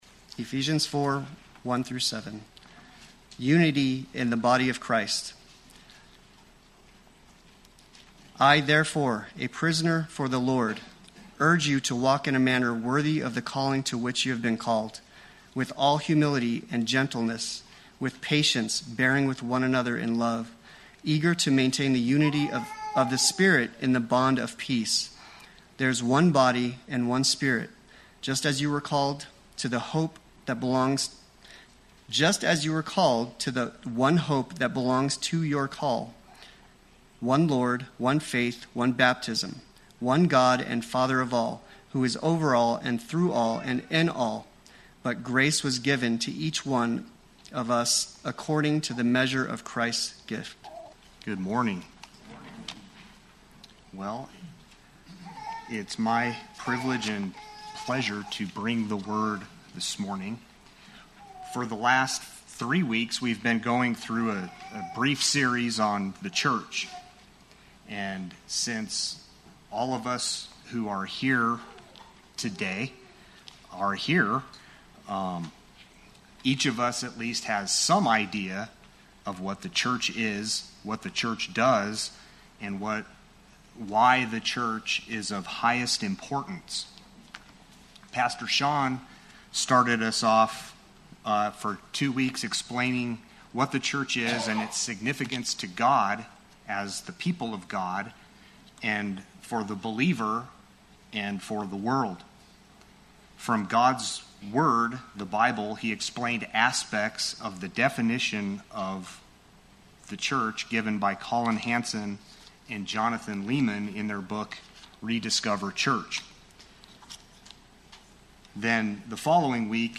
Play Sermon Get HCF Teaching Automatically.
The Gospel Pictured Sunday Worship